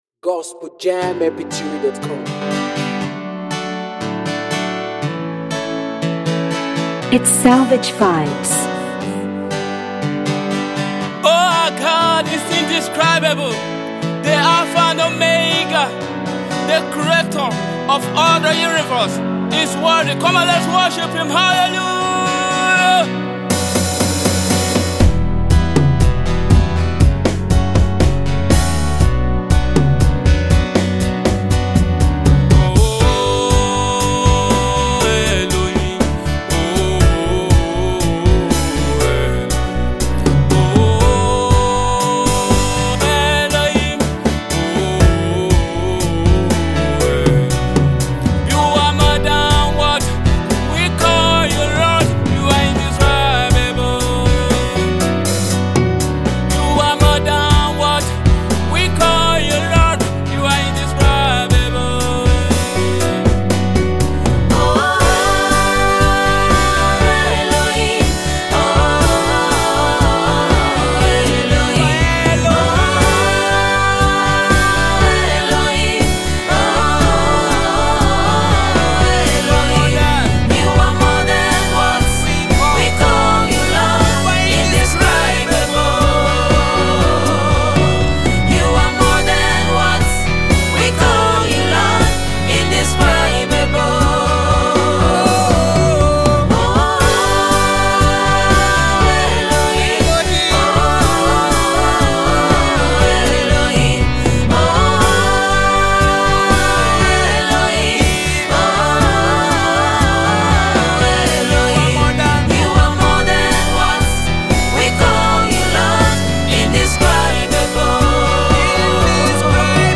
Nigerian gospel music minister